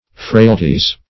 frailty \frail"ty\ (fr[=a]l"t[y^]), n.; pl. frailties